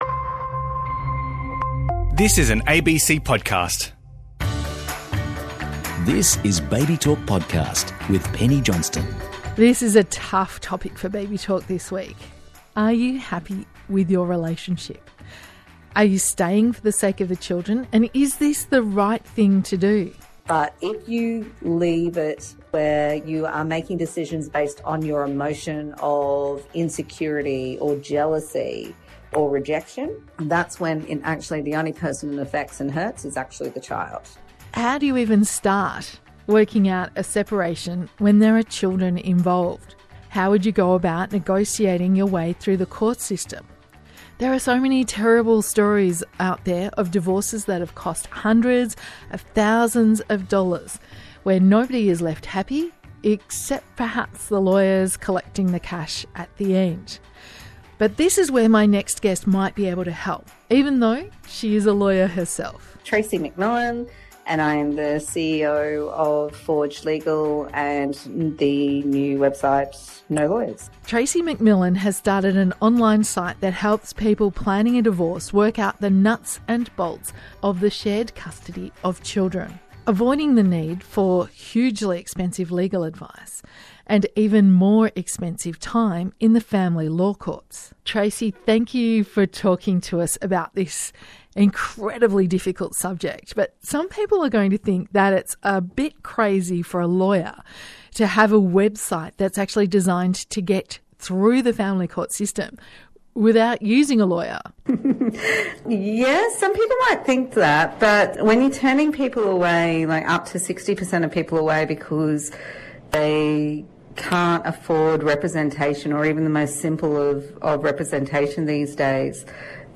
ABC Babytalk interviews